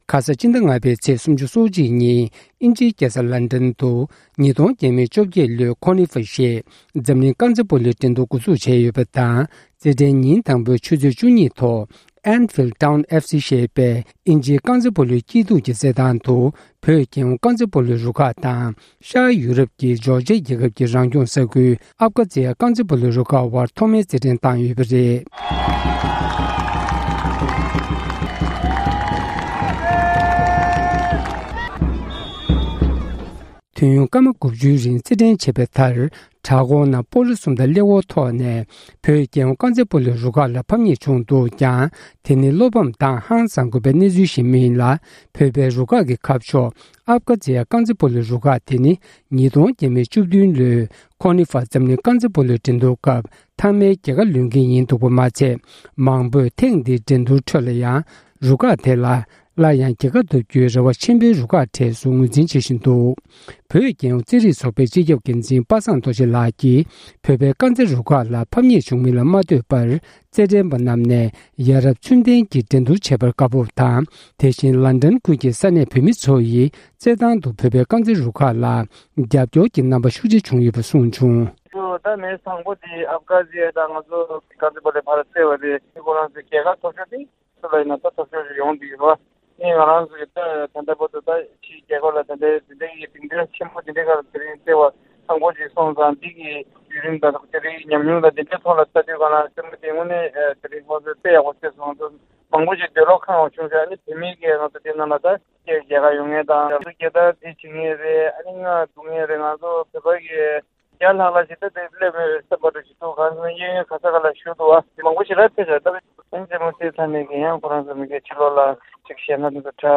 ཀོ་ནི་ཕའི་འགྲན་བསྡུར་ཐེངས་དང་པོའི་གྲུབ་འབྲས། སྒྲ་ལྡན་གསར་འགྱུར།